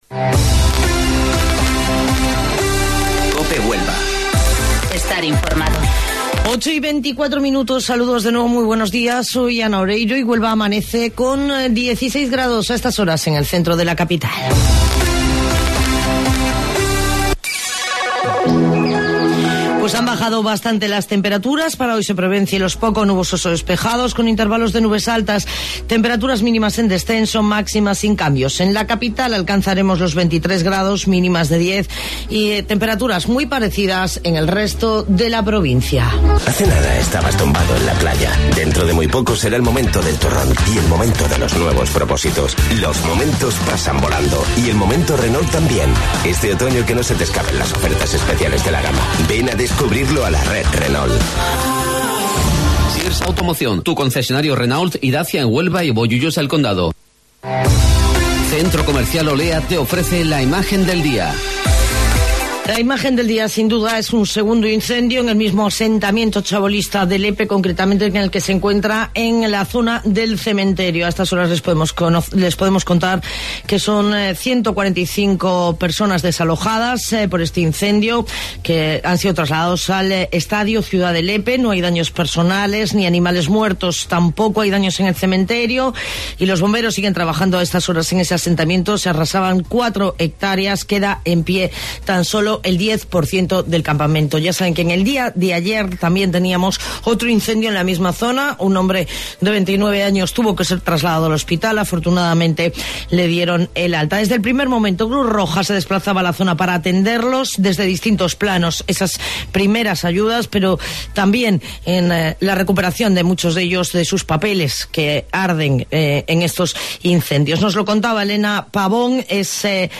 AUDIO: Informativo Local 08:25 del 15 de Octubre